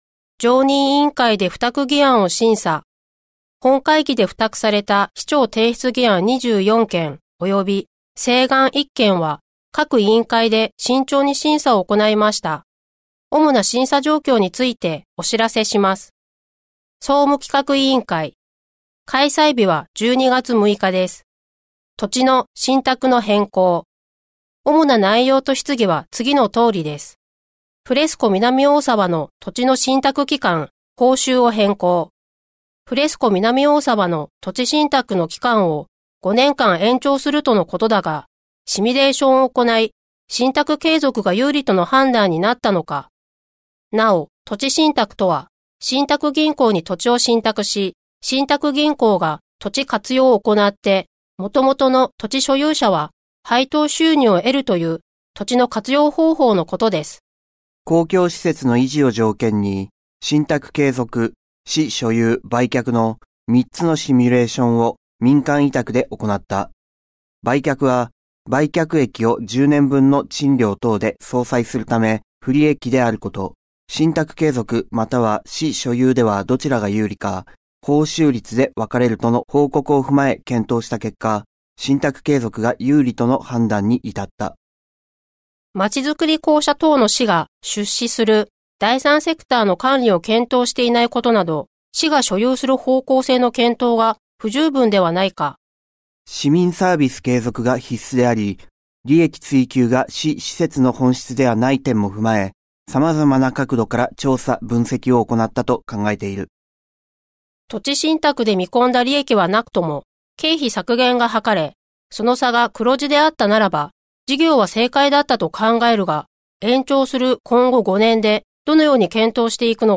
「声の市議会だより」は、視覚に障害のある方を対象に「八王子市議会だより」を再編集し、音声にしたものです。